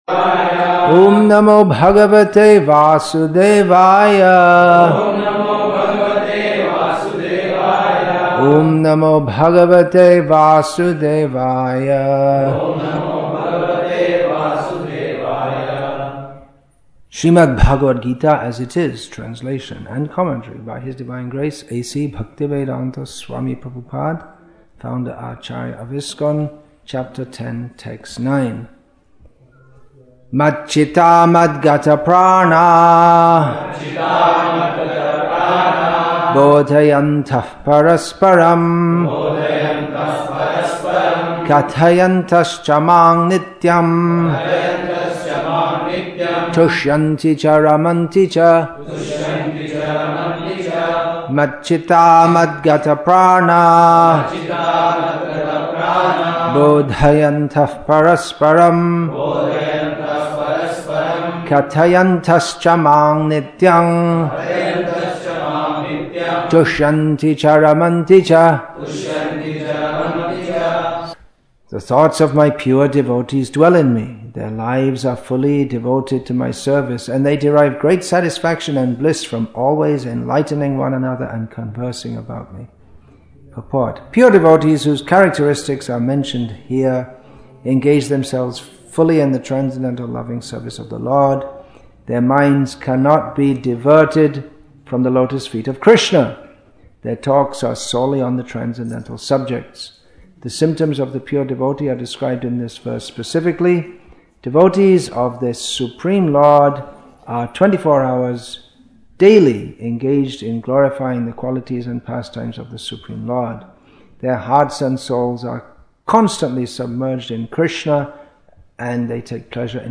The Krishna Addiction August 21, 2013 Bhagavad-gītā Devotee , Educational/Informative , Sādhana-bhakti English with தமிழ் (Tamil) Translation; Vellore, Tamil Nadu , India Bhagavad-gītā 10.9 1 h 8 m 37 s 32.94 MB Download Play Add To Playlist Download